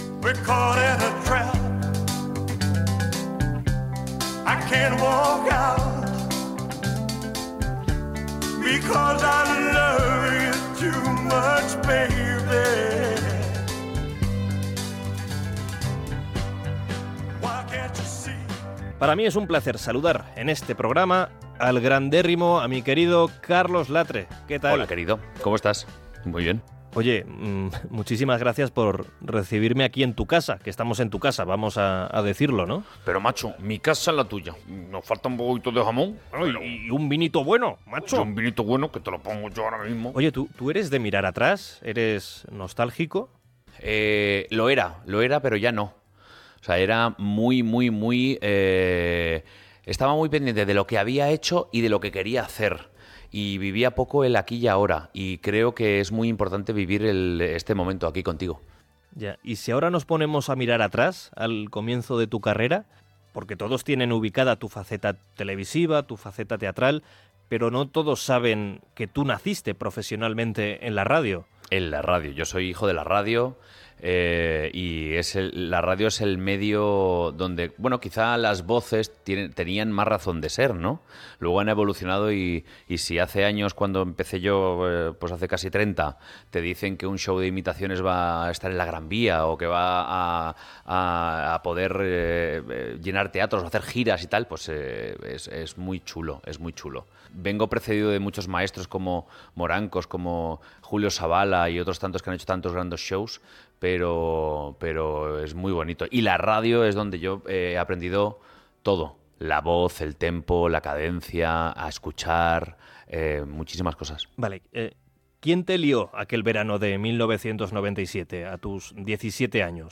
Fragment d'una entrevista a l'humorista i imitador Carlos Latre
Entreteniment
FM